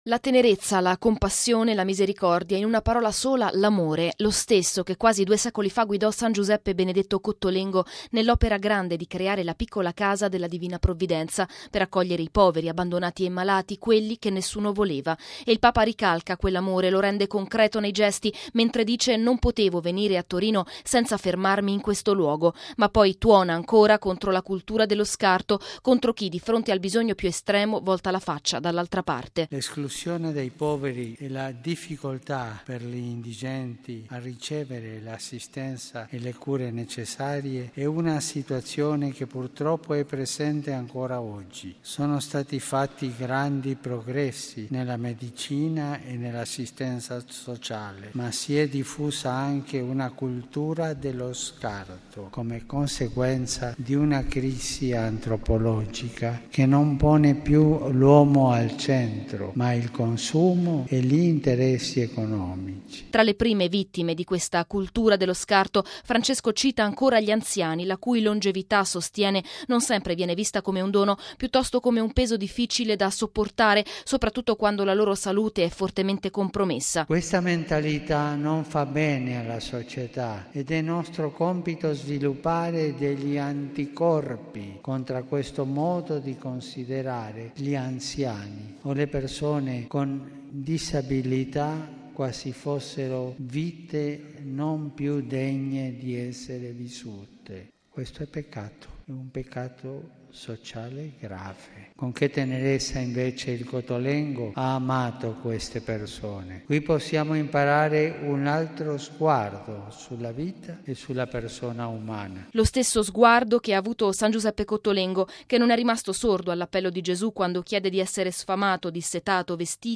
Francesco nella Piccola Casa della Divina Provvidenza di Torino, conosciuta come “Cottolengo”, ha incontrato gli anziani, i malati e i disabili.